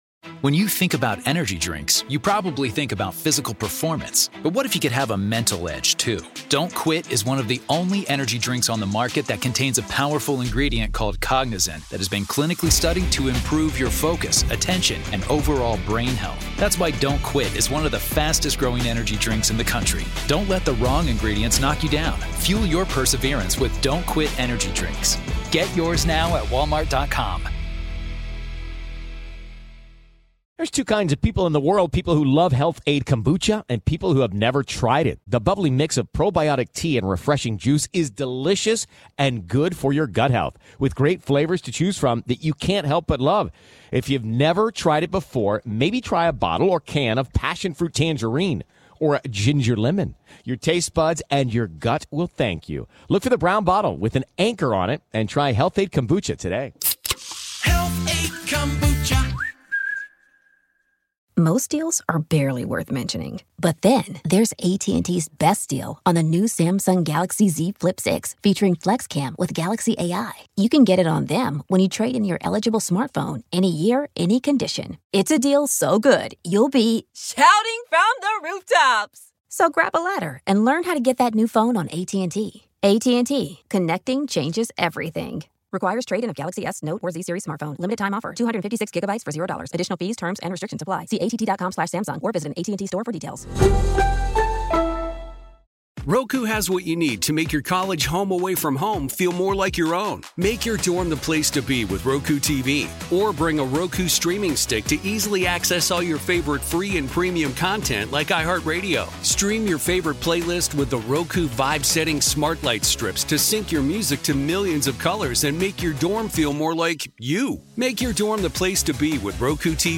On this episode of Â Our American Stories, Johnny Cash is an American original, and this story is an insider's look at the man whose music sprang from the way he lived. Pastor Greg Laurie is here to tell the story from his recent book/documentary, Â Johnny Cash: The Redemption of an American Icon.